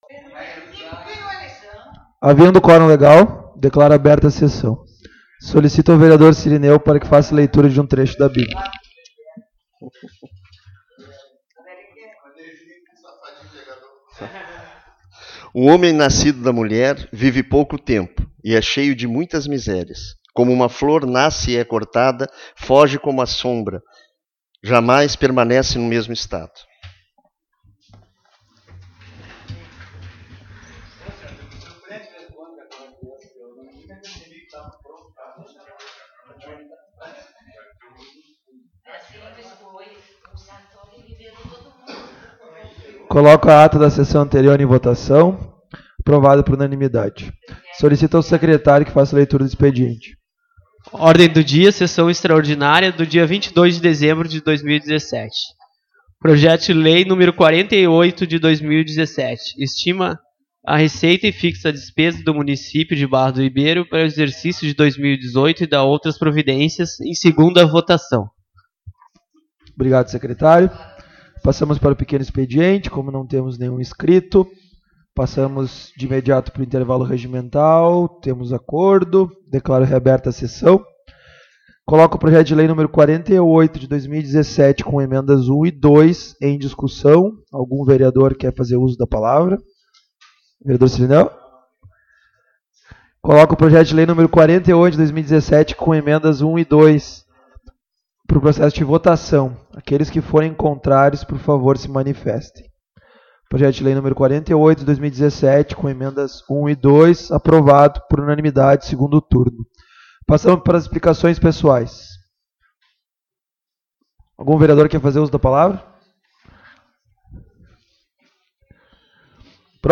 Publicação: 24/02/2021 às 11:06 Abertura: 24/02/2021 às 11:06 Ano base: 2017 Número: Palavras-chave: Anexos da publicação Áudio Sessão Extraordinária de 22.12.2017 às 8:00 h 24/02/2021 11:06 Compartilhar essa página...